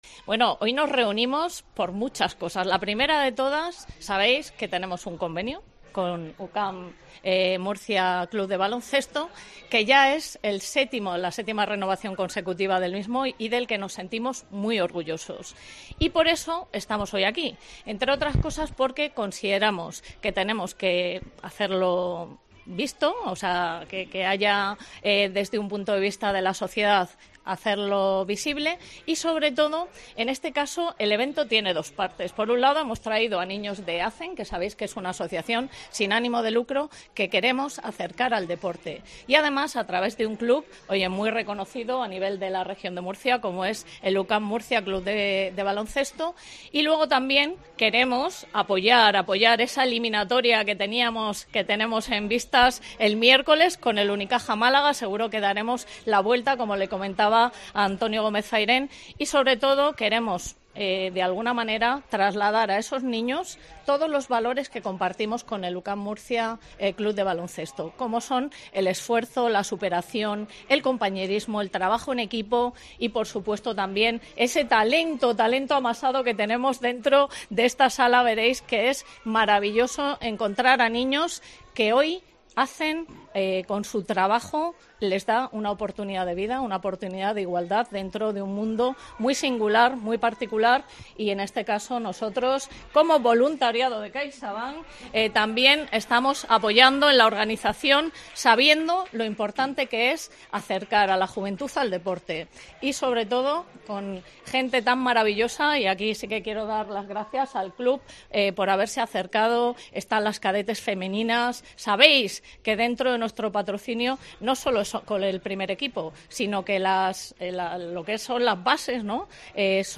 El ala pívot montenegrino, segundo capitán de la plantilla universitaria, hizo esas declaraciones en un acto que tuvo lugar en el salón de actos de la dirección territorial de CaixaBank y en el que participaron más de 70 menores en riesgo de exclusión social.